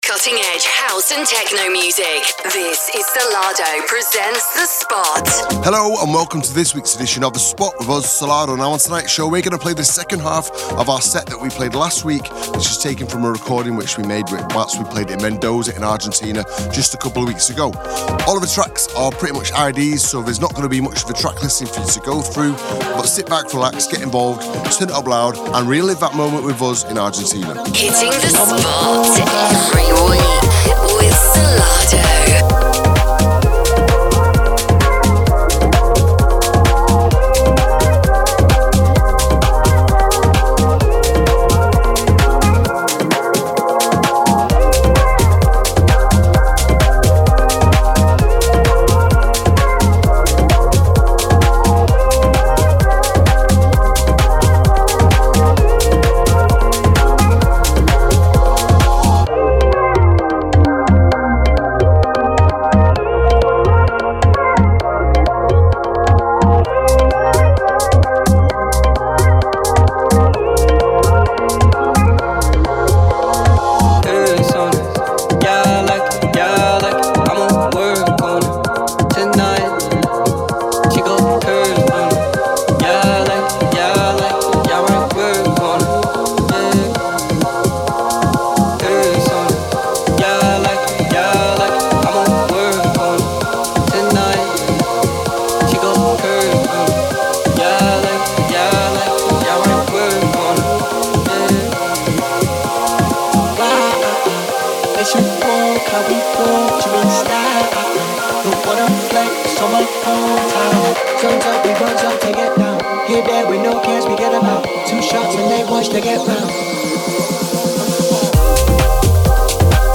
cutting edge house & techno from around the globe
exclusive live mixes
Recorded live